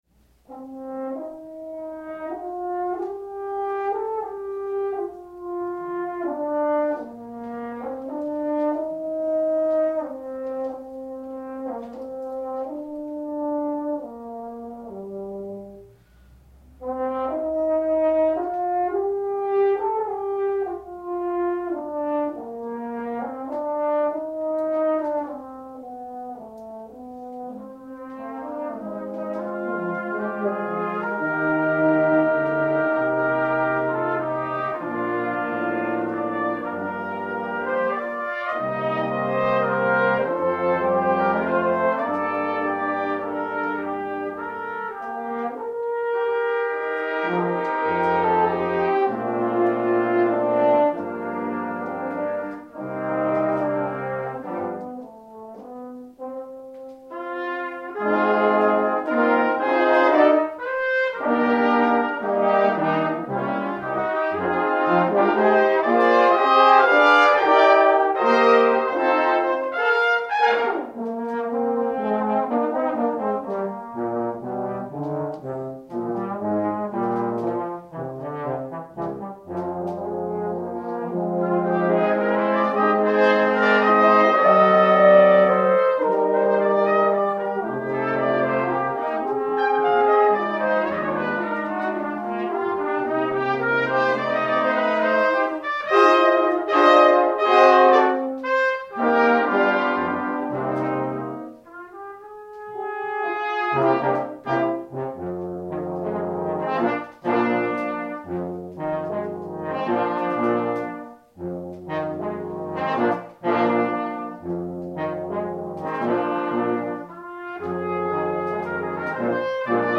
Quintett Phonsinnen
Cornet
Trompete
Tuba
Horn
Posaune